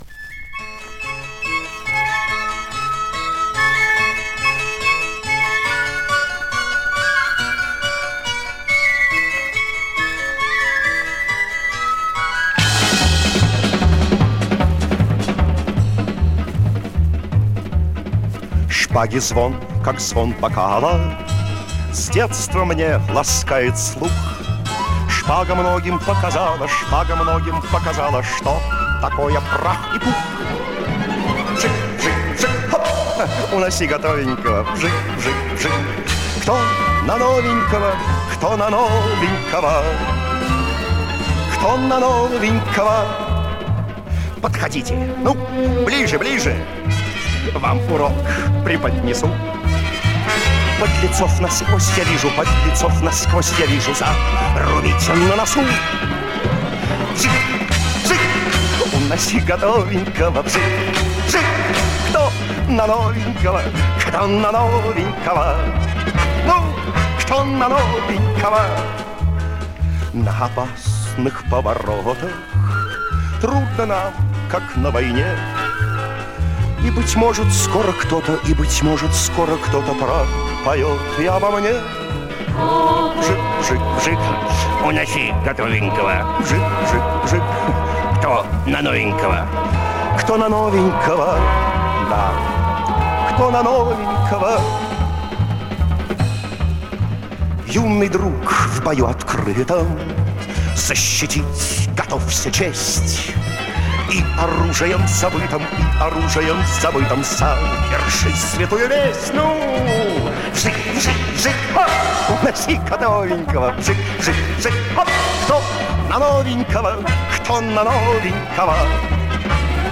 • Качество: Хорошее
• Жанр: Детские песни
советские детские песни